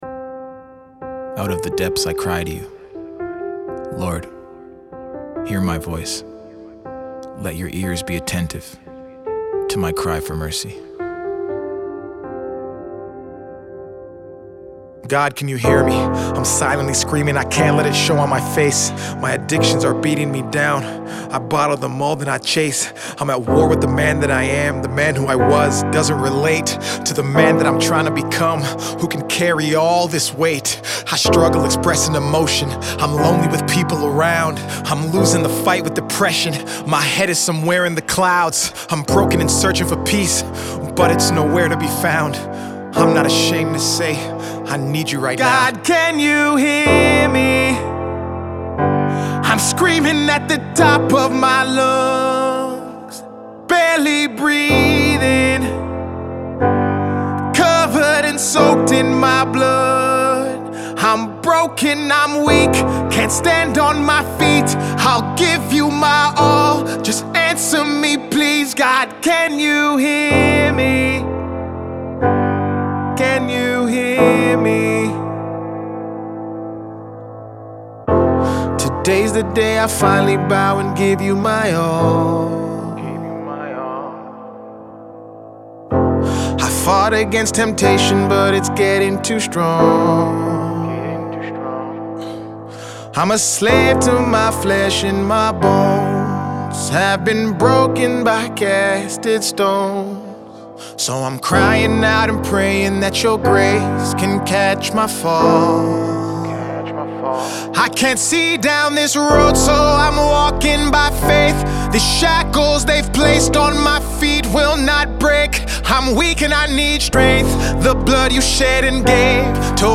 There’s frustration in his voice, but also hope.